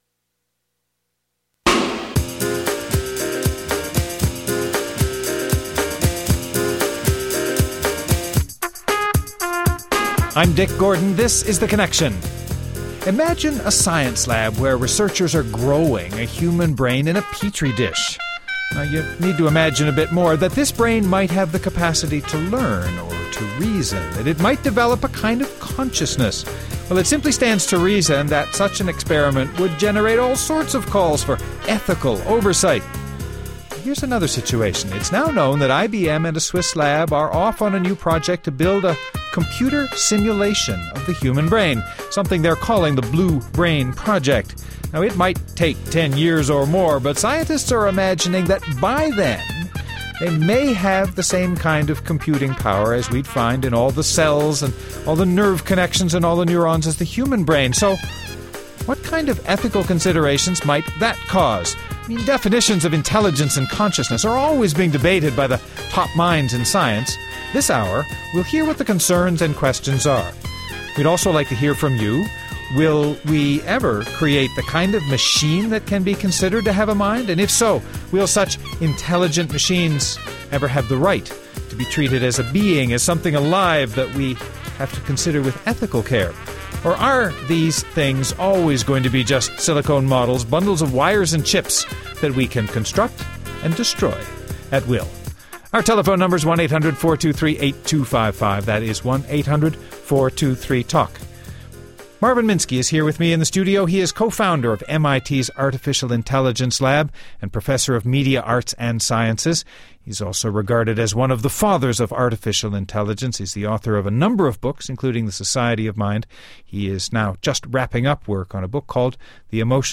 Guests: Marvin Minsky, co-founder of MIT’s Artificial Intelligence Lab and author of numerous books including the forthcoming “The Emotion Machine”